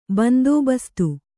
♪ bandōbastu